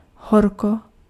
Ääntäminen
IPA: [ʃa.lœʁ]